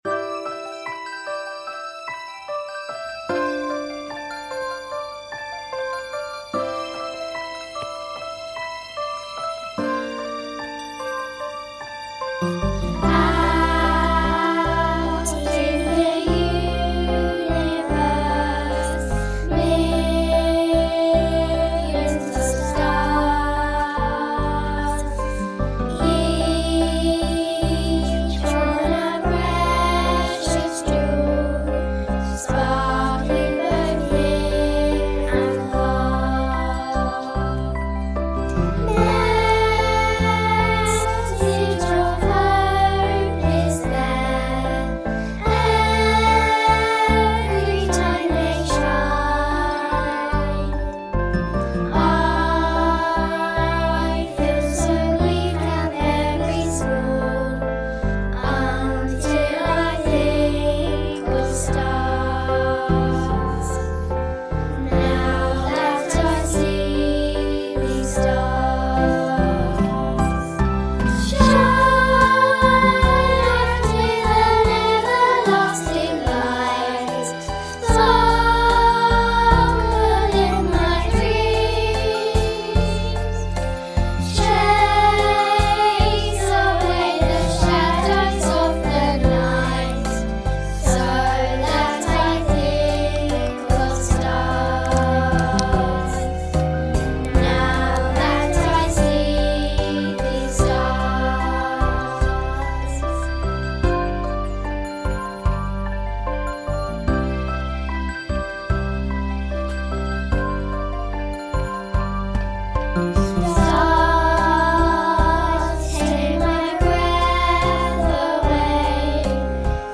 Stars - Yr 3/4 choir